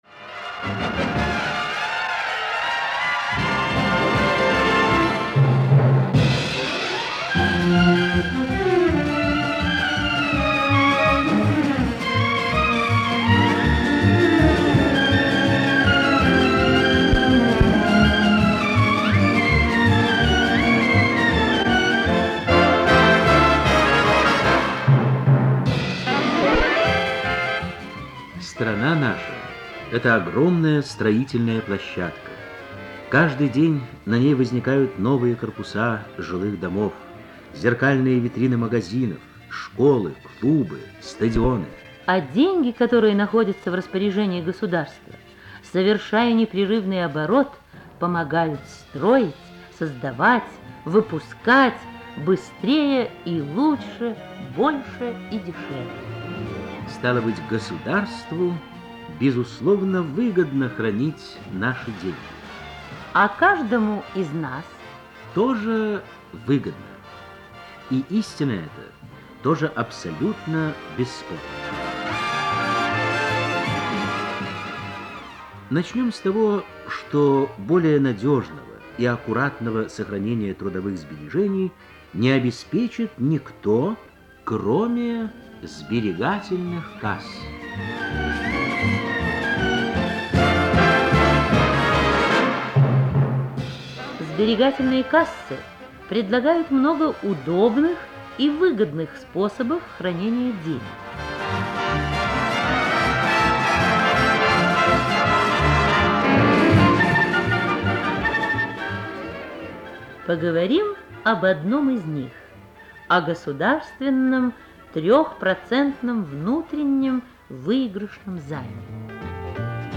Социальная реклама в СССР
В течении всей передачи звучит Весёлая минута Александра Лукиновского.